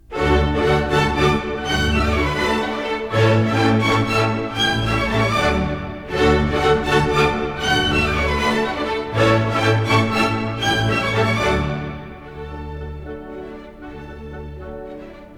Stereo recording made in April 1957 in the
Orchestral Hall, Chicago